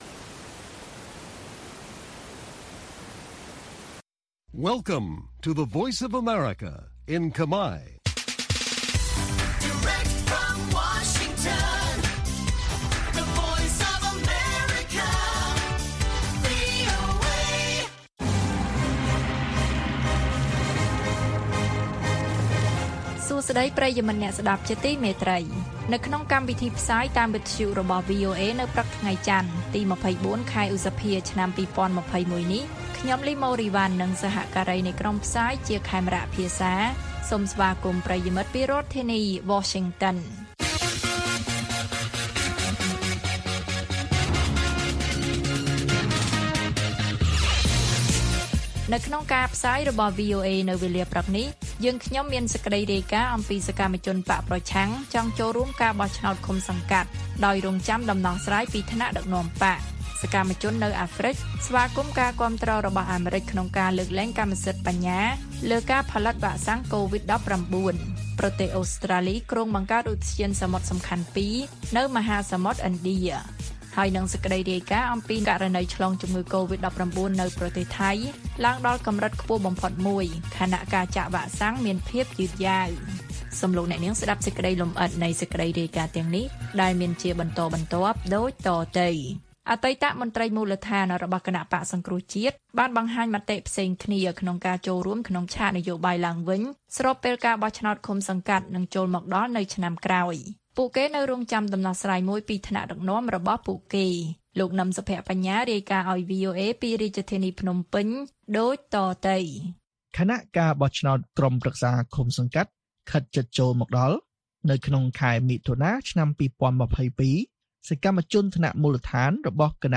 ព័ត៌មានពេលព្រឹក៖ ២៤ ឧសភា ២០២១